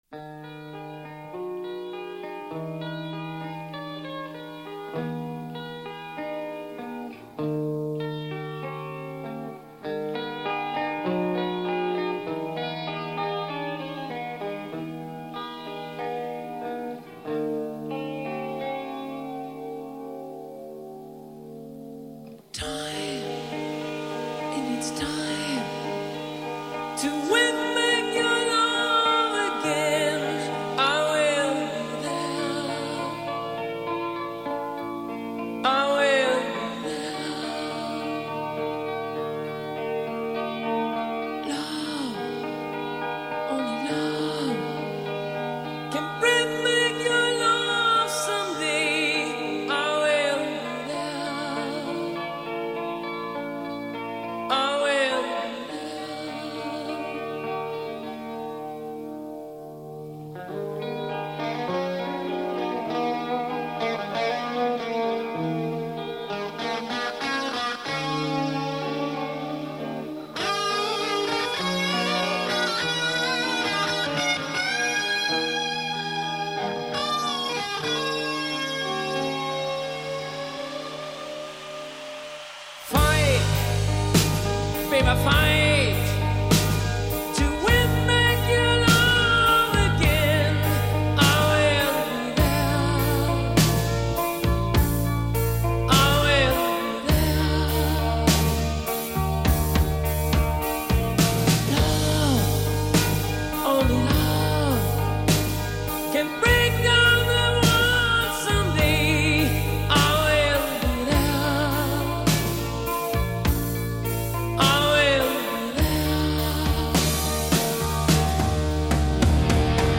اجرای زنده آکوستیک در پرتغال
Rock, Acoustic, Classic Rock